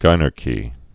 (gīnärkē, jĭnär-)